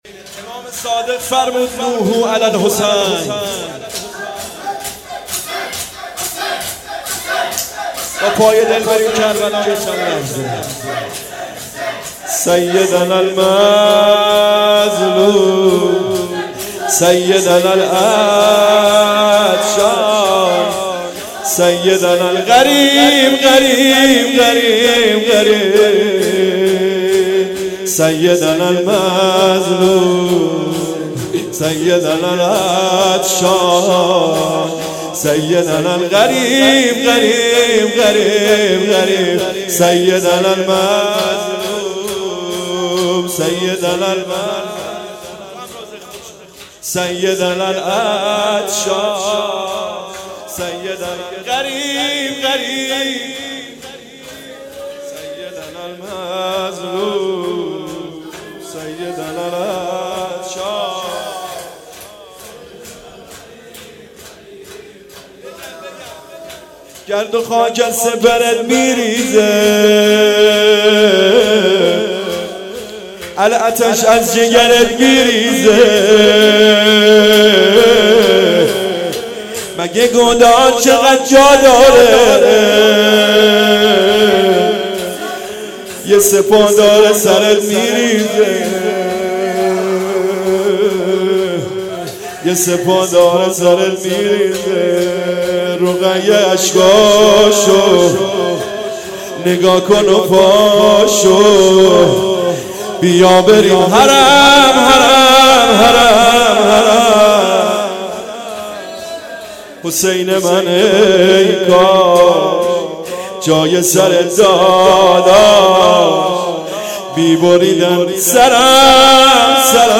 شهادت امام صادق علیه السلام-شب سوم